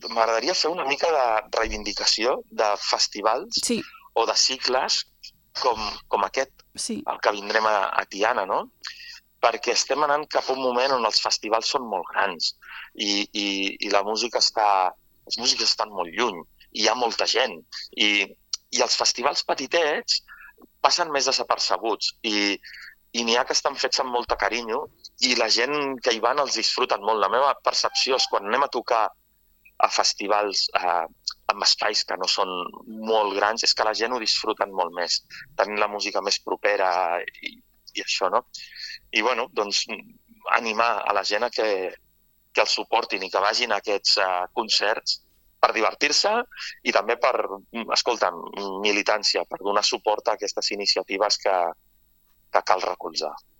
amb qui fem l’entrevista.